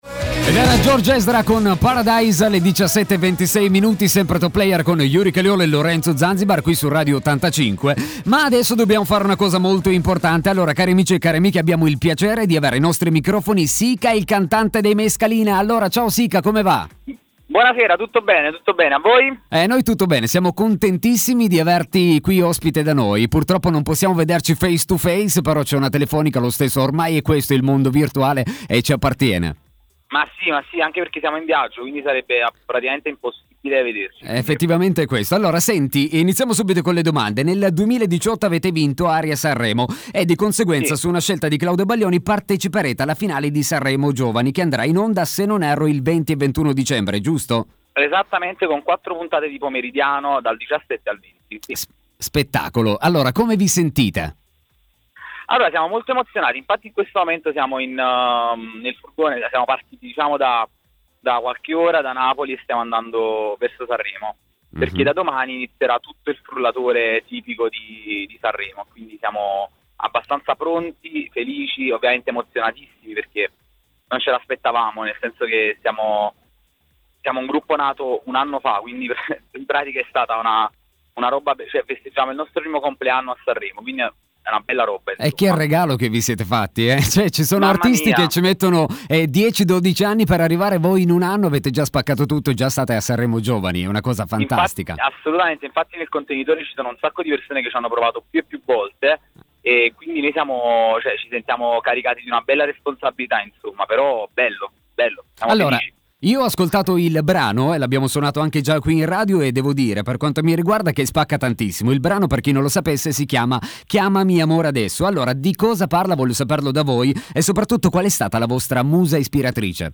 Top Player – Intervista ai Mescalina